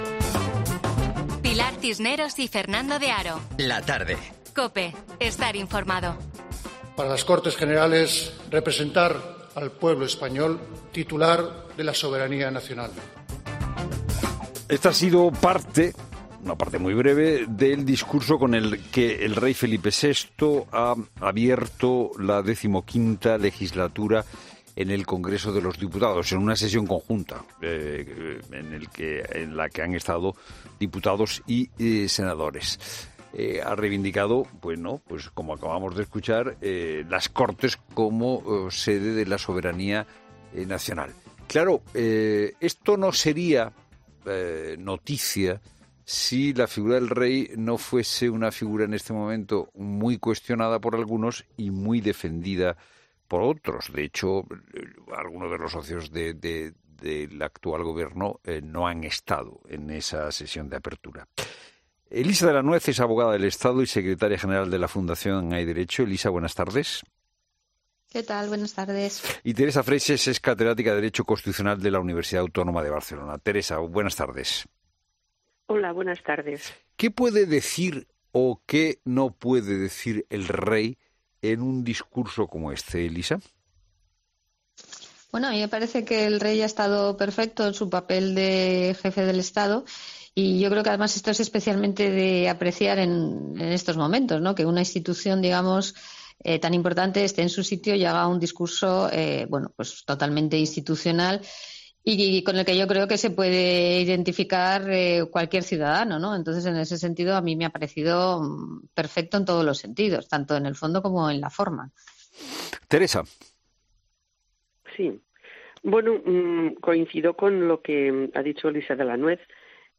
Lo analizan los expertos en 'La Tarde' de COPE.